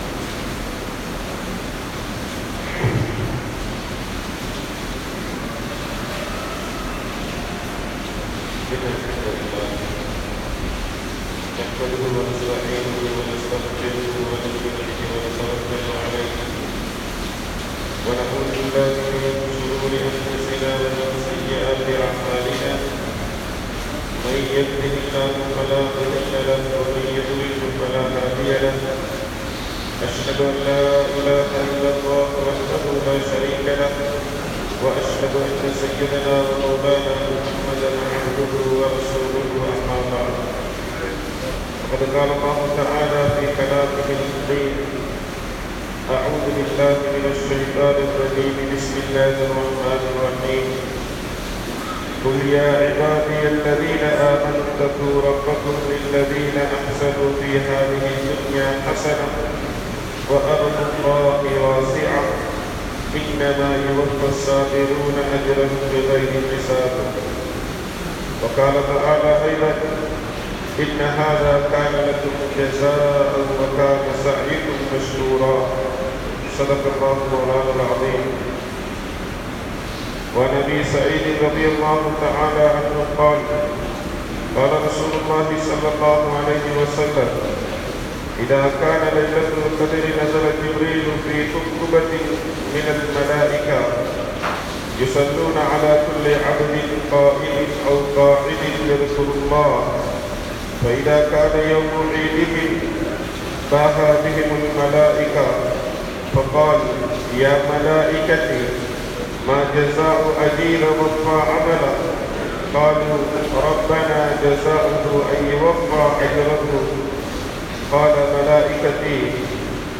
Listen to Friday (Jumah) sermons and Islamic lectures from Muslim Central.
Last Friday of Ramadan Jumah Bayan